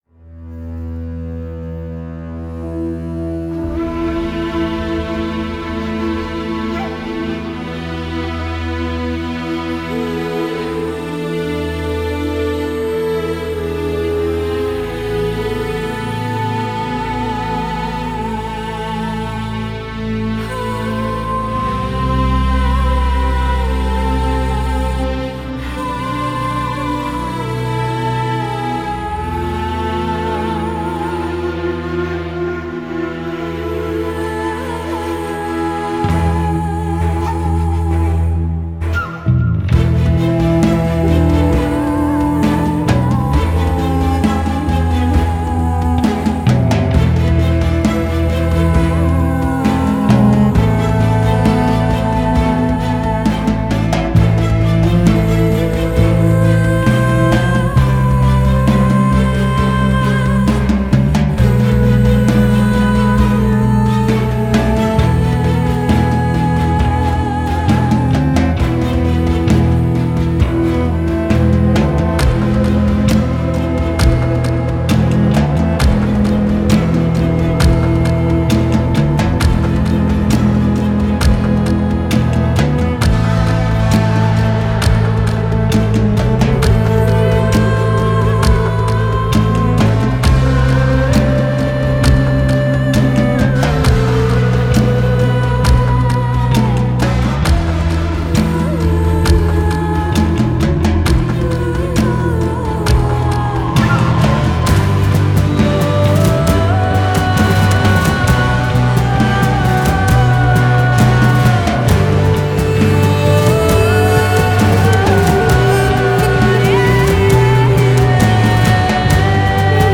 synth
вокал
bass
duduk